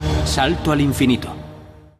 Die 4. Staffel erhielt auch den spanischen Titel "Salto al infinito" und wird so am Ende des Vorspannes von einer männlichen Off-Stimme genannt.